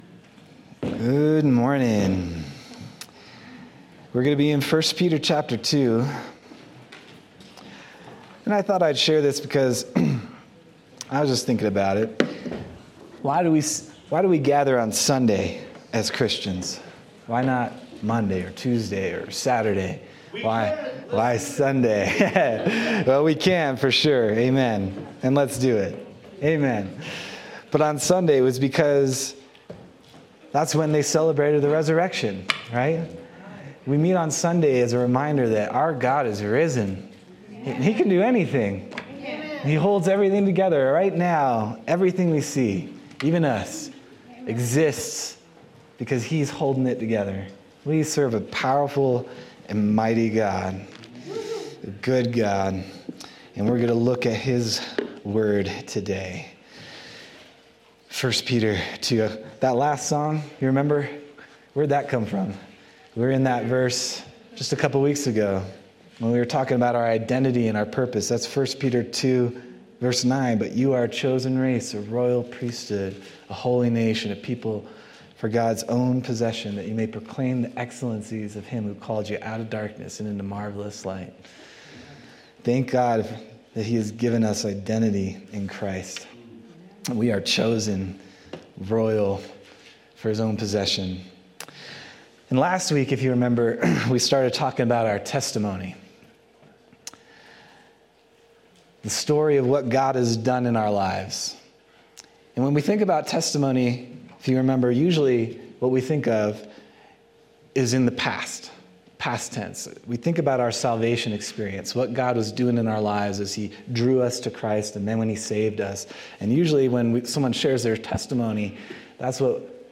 February 23rd, 2025 Sermon